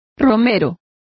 Complete with pronunciation of the translation of pilgrim.